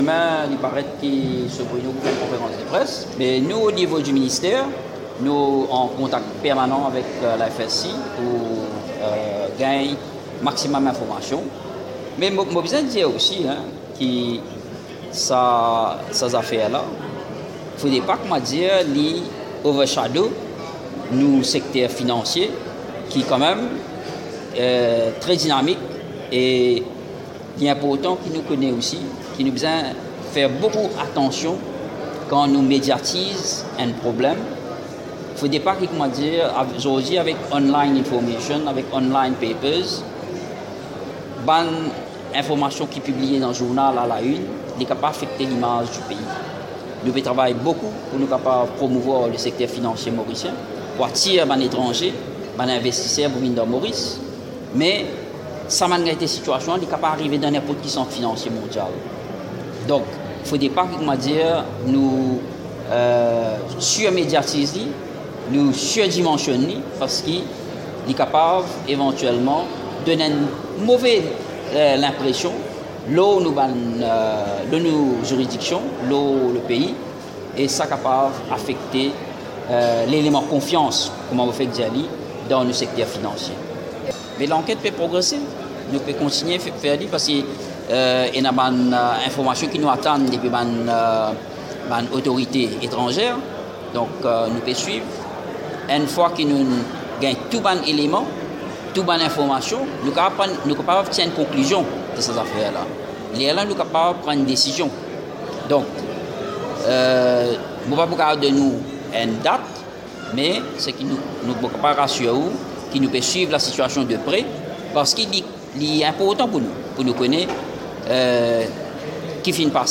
L’édition de cette année a eu lieu à l’hôtel Hennessy Park à Ebène.